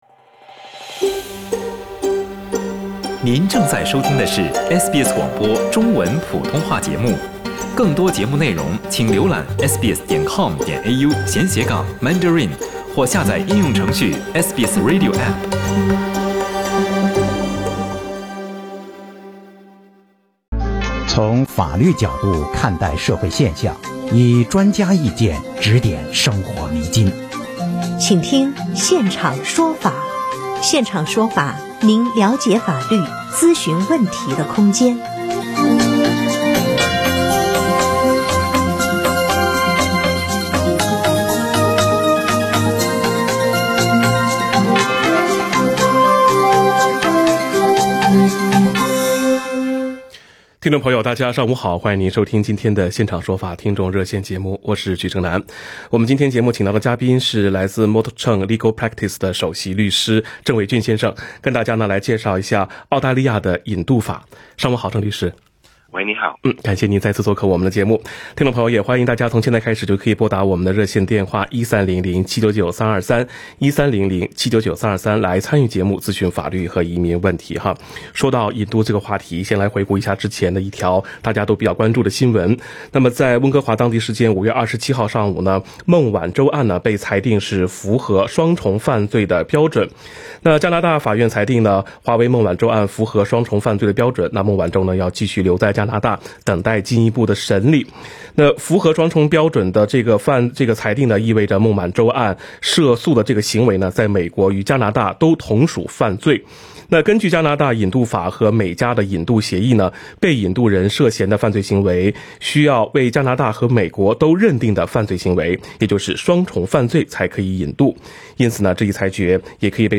本期《现场说法》听众热线